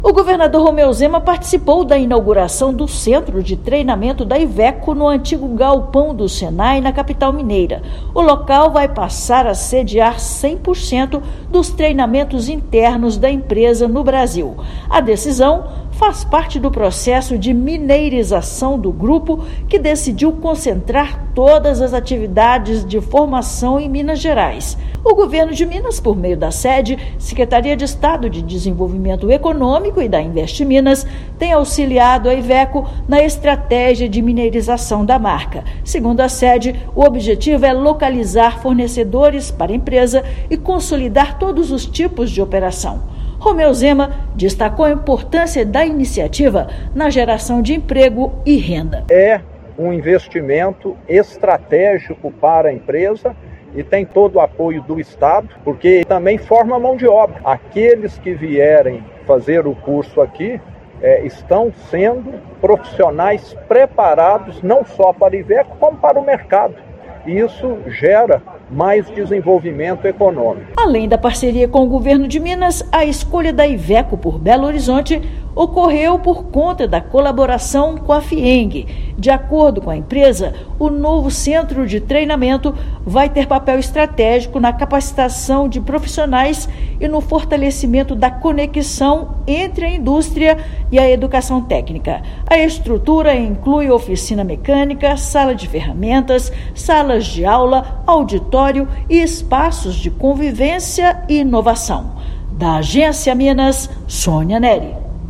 [RÁDIO] Com apoio do Governo de Minas, Iveco inaugura centro de treinamento e fortalece economia e mão-de-obra no estado
Iveco Academy, em Belo Horizonte, passará a sediar 100% das atividades internas da companhia no Brasil. Ouça matéria de rádio.